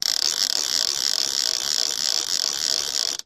Baby Toy Spinner